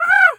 crow_raven_call_squawk_05.wav